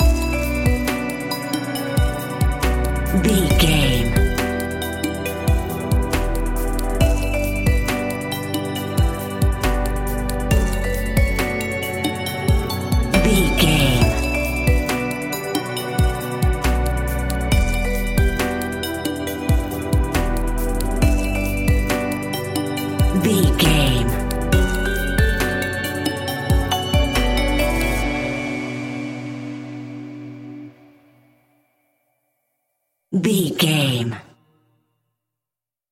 Chilled Tropical Hip Hop 30 Sec.
Aeolian/Minor
groovy
dreamy
tranquil
smooth
drum machine
synthesiser
synth bass